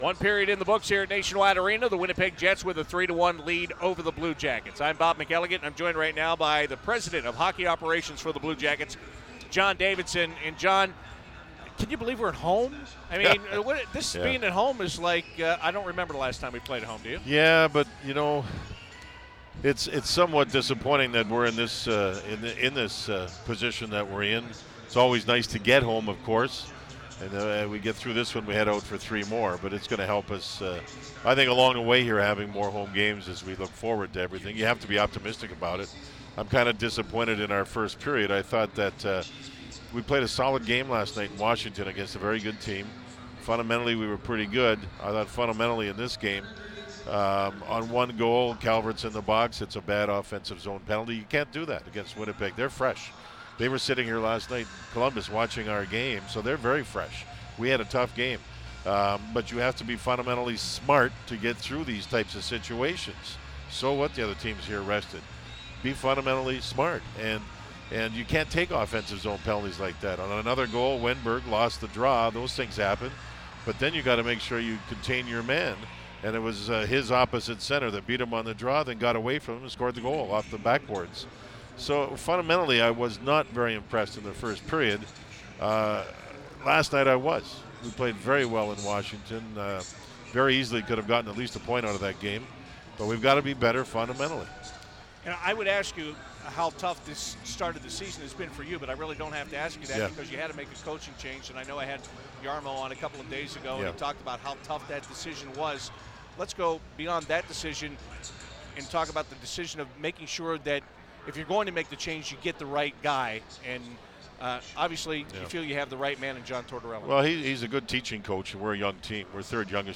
CBJ Interviews